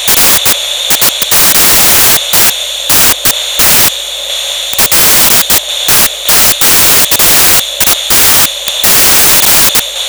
This allows you to correct the speed of the chuff against the actual speed of the loco.
Moving Off Picking up
Speed
misty_speeding_up.wav